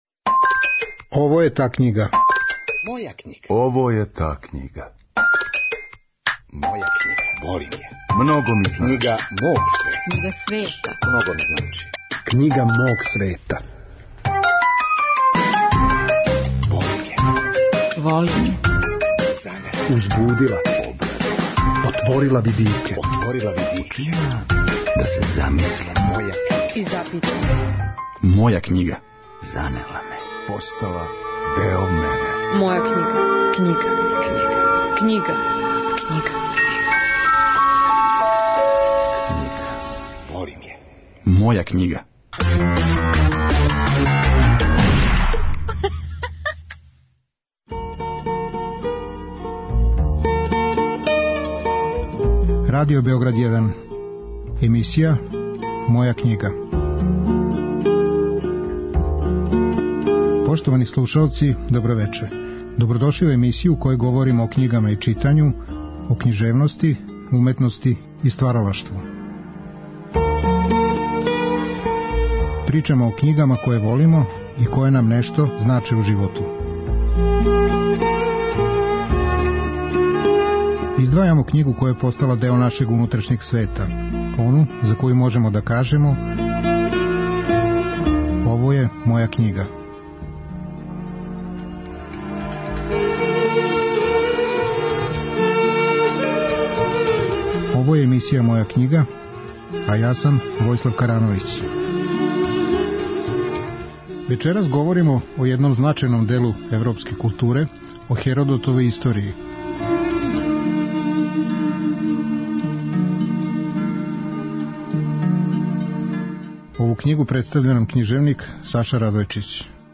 Вечерас разговарамо о Херодотовој 'Историји'.